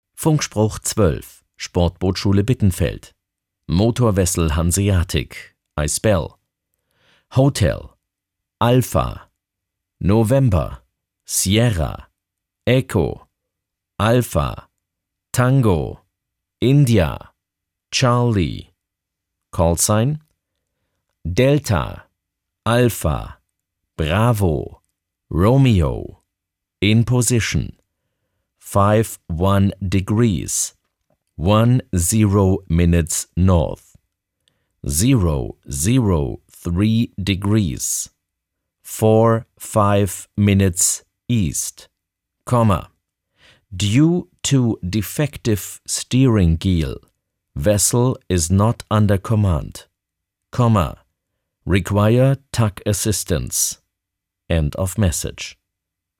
Funkspruch-12.mp3